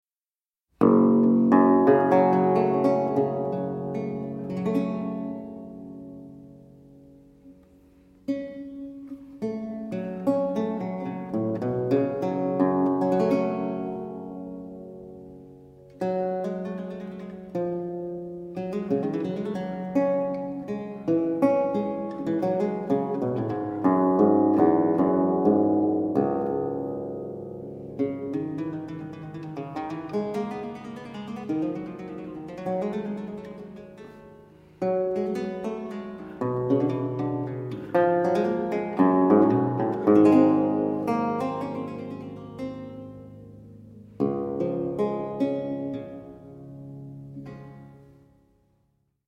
Baroque Guitar and Theorbo
Italská kytarová a theorbová hudba ze 17. století
Kaple Pozdvižení svatého Kříže, Nižbor 2014